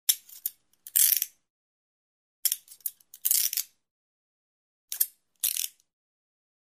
На этой странице собраны различные звуки наручников: от звонкого удара металла до характерного щелчка замка.
Звук щелчка ручек наручников